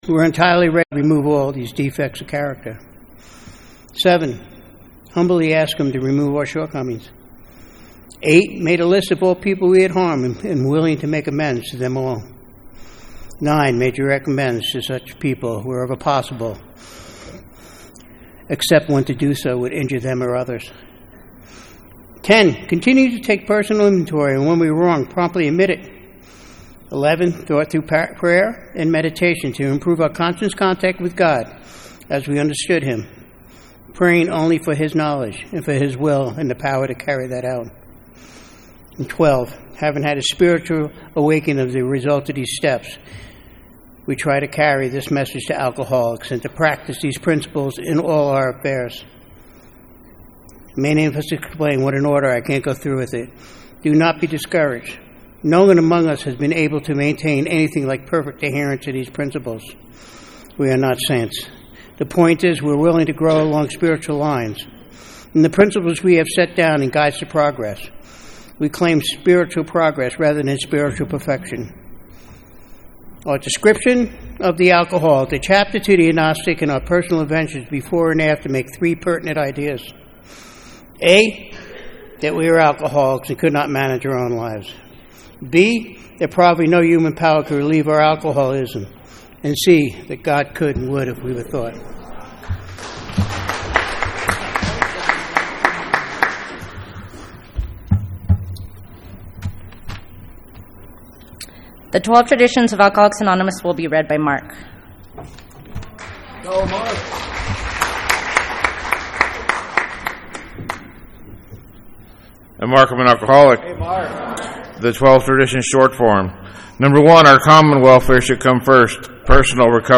50th Annual Antelope Valley Roundup